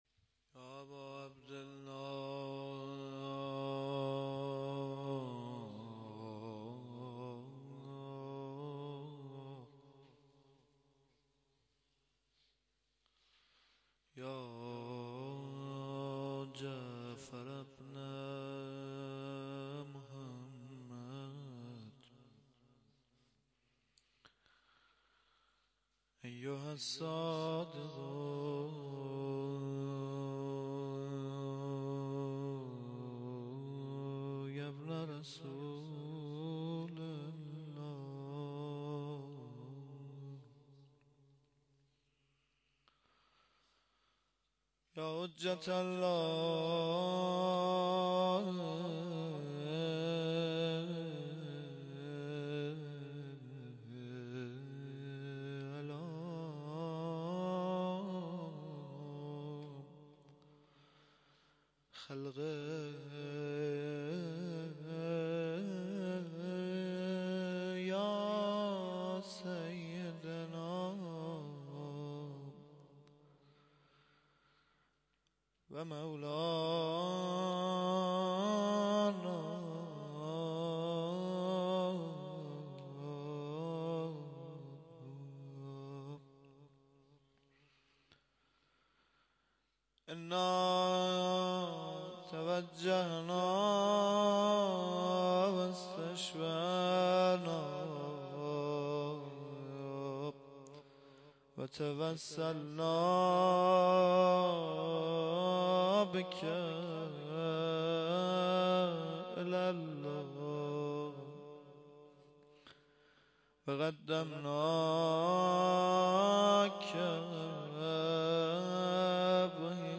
شهادت امام صادق-02-روضه
02-shahadat-emam-sadegh-roze.mp3